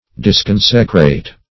Search Result for " disconsecrate" : The Collaborative International Dictionary of English v.0.48: Disconsecrate \Dis*con"se*crate\, v. t. To deprive of consecration or sacredness.